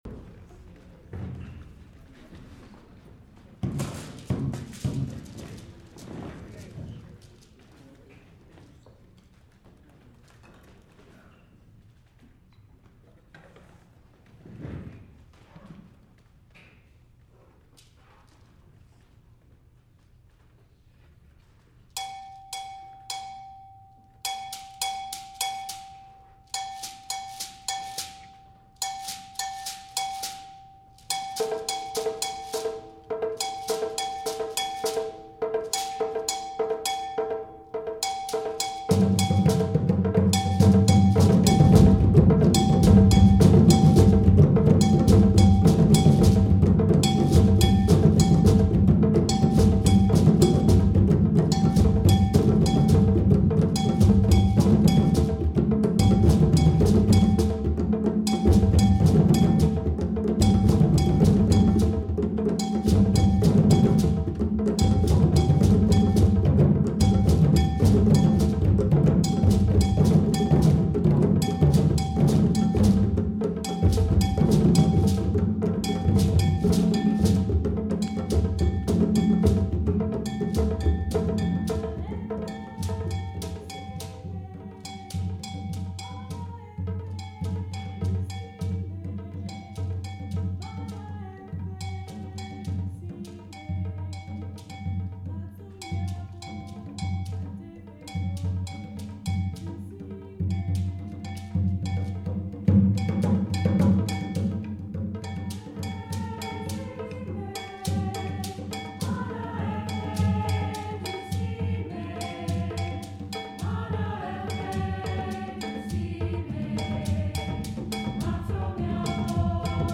UVM Percussion Ensemble audio from S07 concert (recording quality not the best)
Boboobo is the most common social music and dance of the Northern Ewe people of Ghana, who live on the border of Togo in a region formerly known as German Togoland.